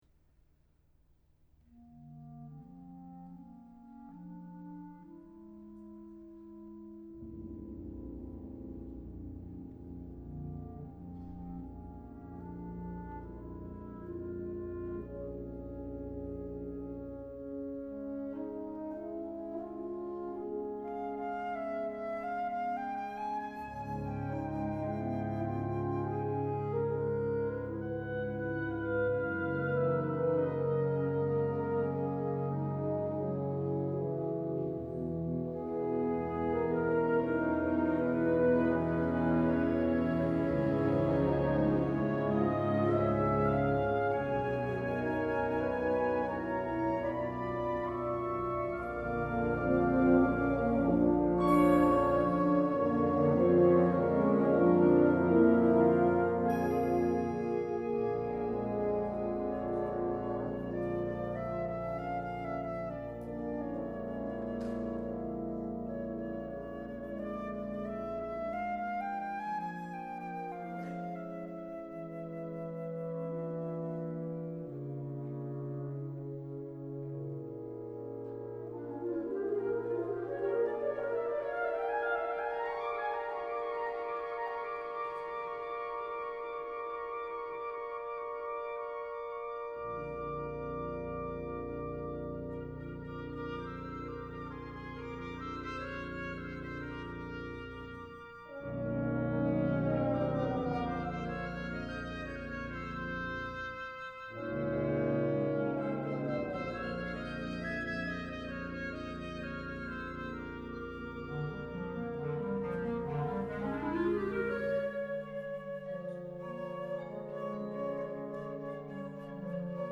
編成：吹奏楽 楽曲構成：全3楽章